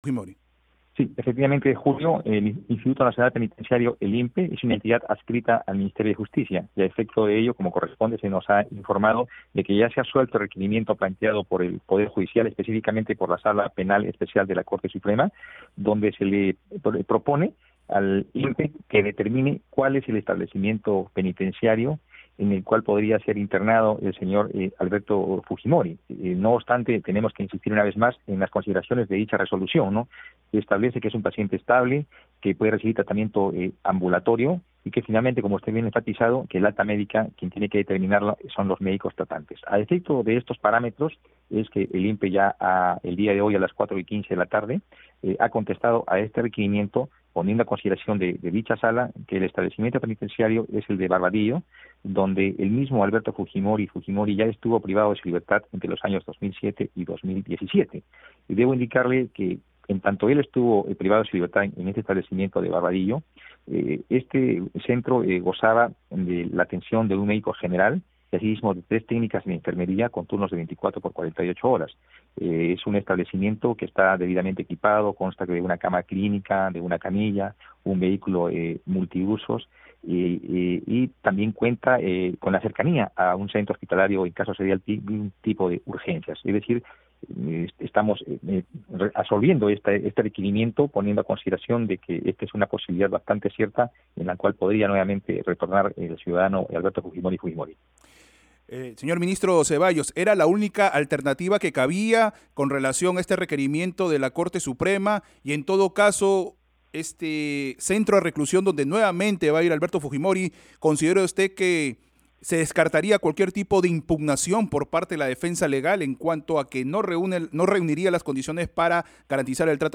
Declaraciones de Ministro de Justicia Vicente Zeballos